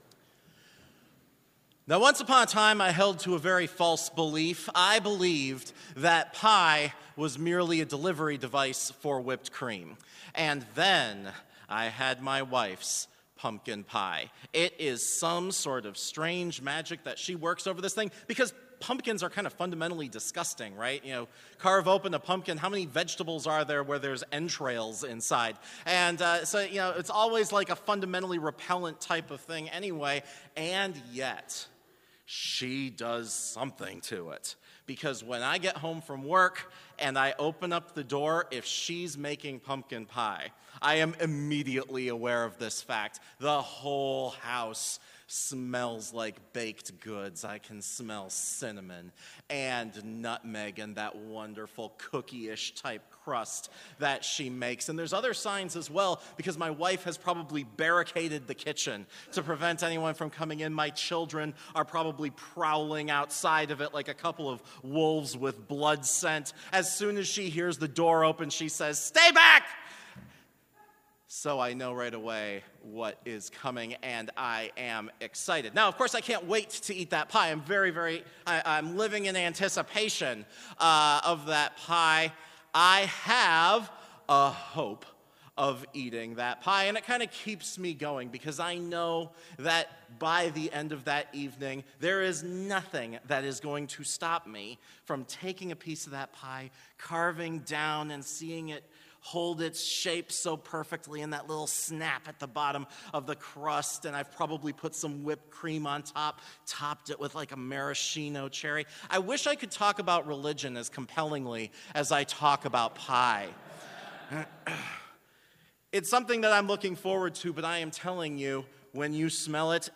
Complete service audio for Chapel - November 12, 2019
Prelude
Devotion Prayer Hymn 525 - I Know of a Sleep in Jesus' Name View vv. 5 & 7 Blessing Postlude